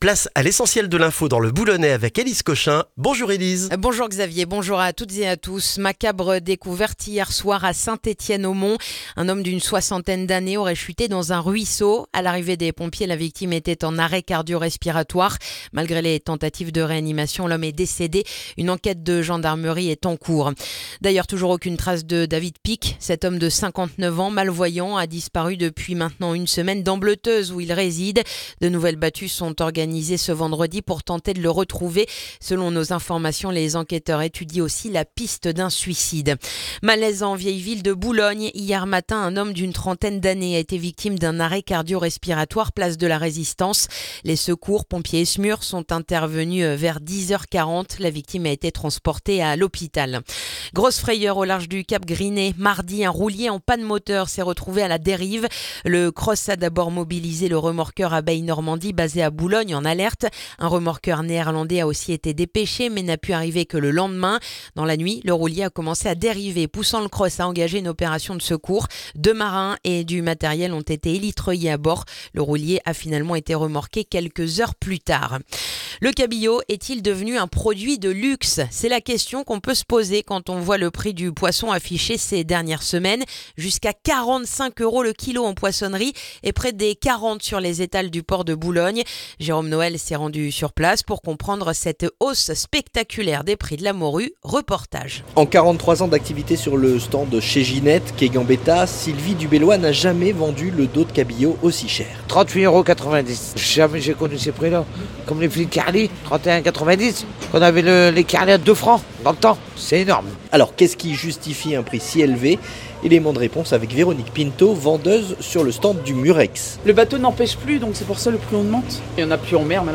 Le journal du vendredi 23 janvier dans le boulonnais